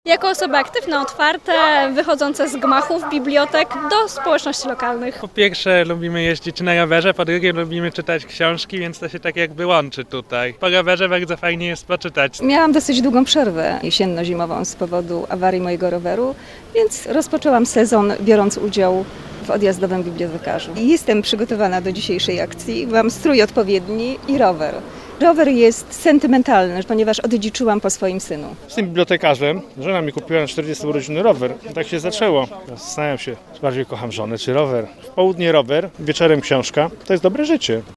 Posłuchaj, co o imprezie mówią jej uczestnicy: Nazwa Plik Autor Odjazdowy Bibliotekarz audio (m4a) audio (oga) “Odjazdowy Bibliotekarz” to akcja ogólnopolska, zapoczątkowana w Łodzi w 2010 roku.